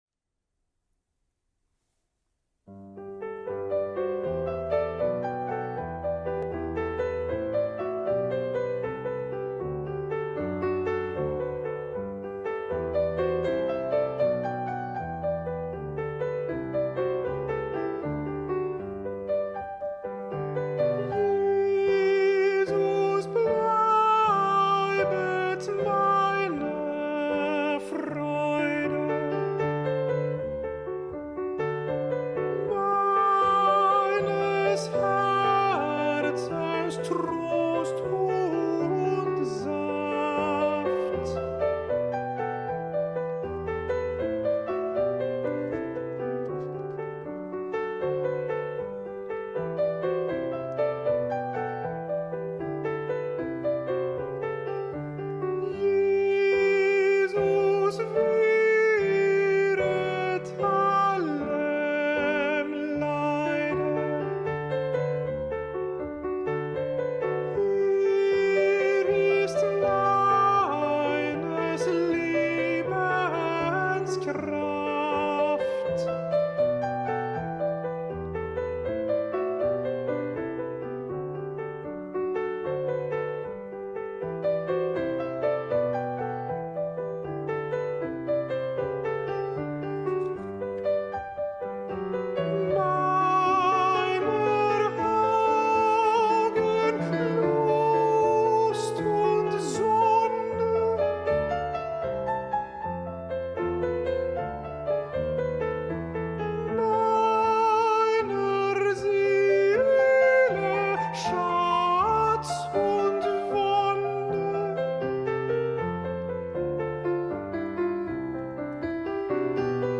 alto
cantate-147Jesus-bleibet-alto.mp3